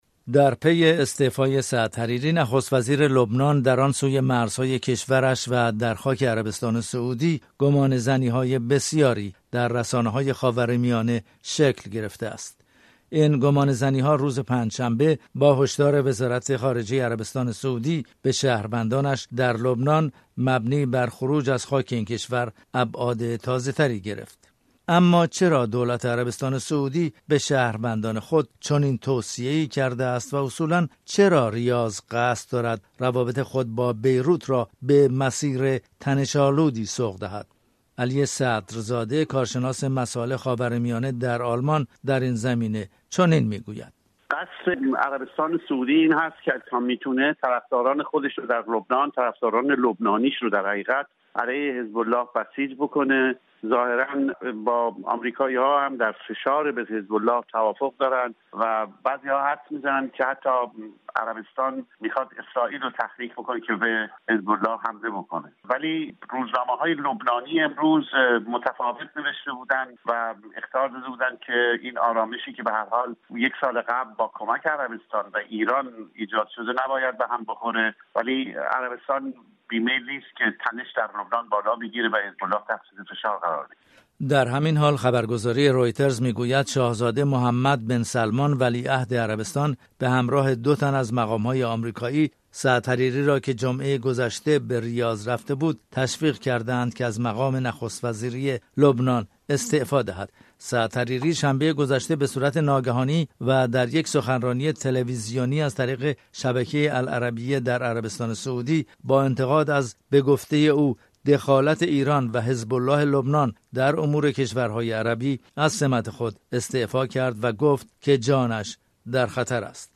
استعفای سعد حریری در هفته جاری و در ریاض، پایتخت عربستان موجب شد که برخی رسانه ها آن را تحت فشار مقام های سعودی بدانند. رادیو فردا از دو کارشناس مسایل خاورمیانه پرسیده است که چرا سعد حریری نخست وزیر لبنان در خاک عربستان از مقام خود کناره گیری کرده است.